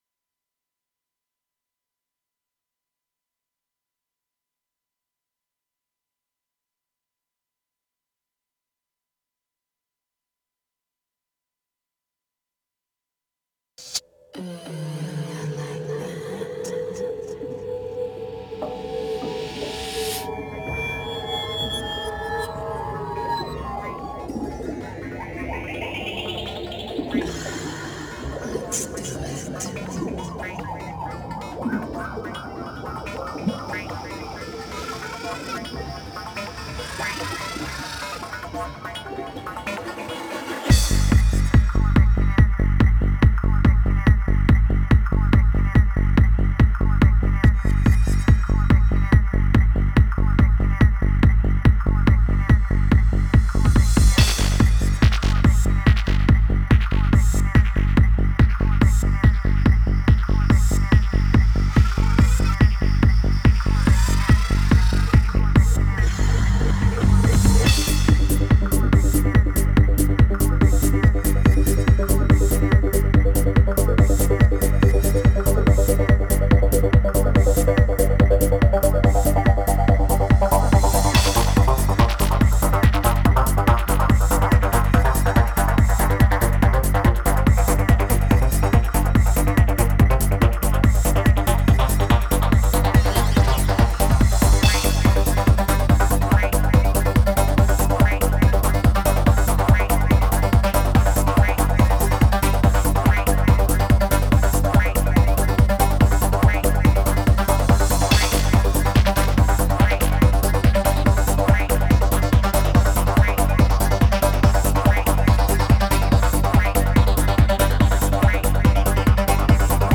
Dit met goa-trance.Het gaat hier puur om het neerzetten van te gekke en vrije Goa-Trance feestjes.
Mixen opgenomen op Svf Feestjes